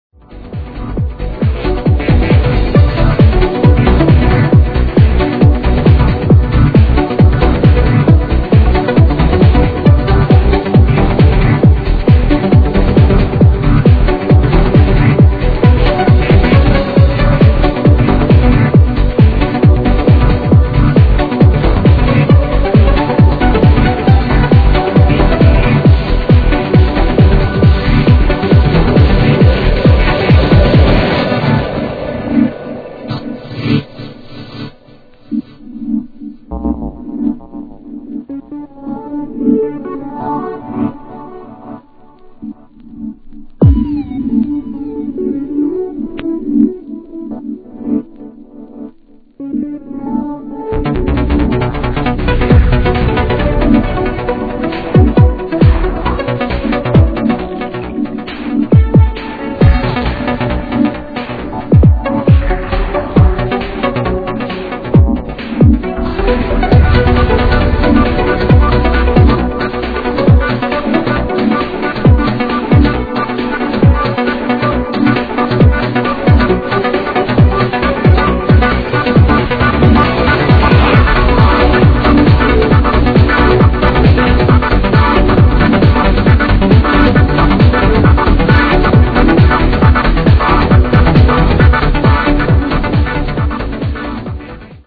Melodic Progressive.